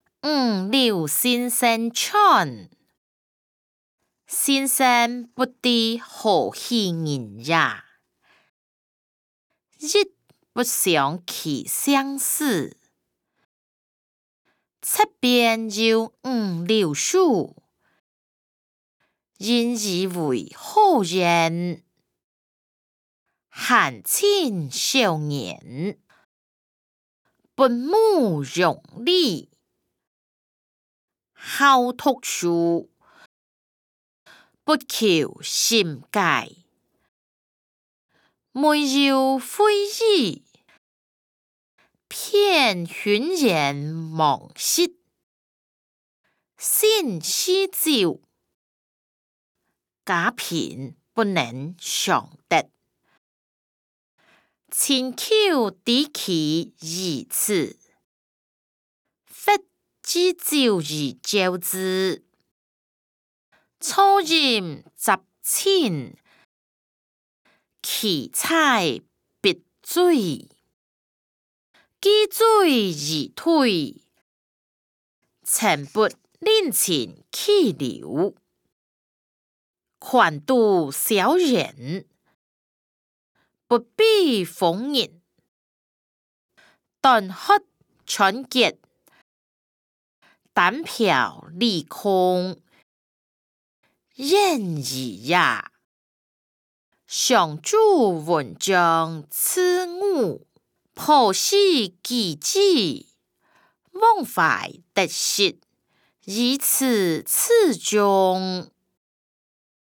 歷代散文-五柳先生傳音檔(大埔腔)